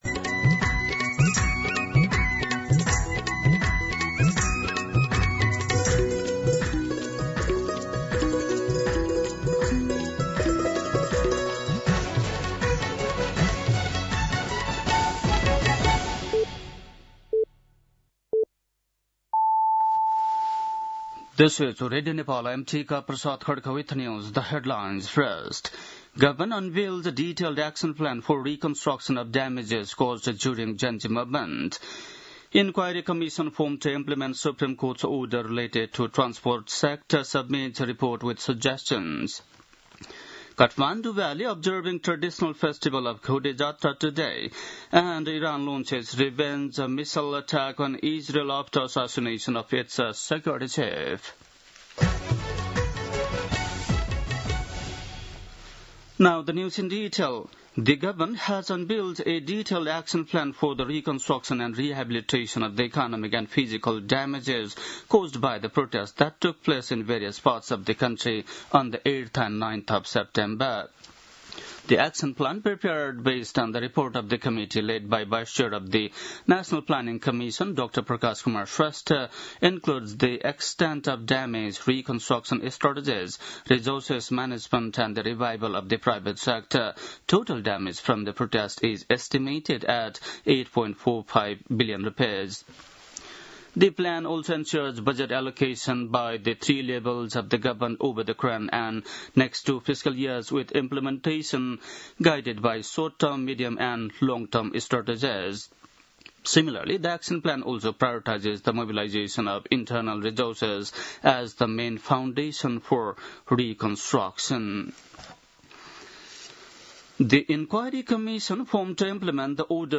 दिउँसो २ बजेको अङ्ग्रेजी समाचार : ४ चैत , २०८२
2-pm-English-Nepali-News.mp3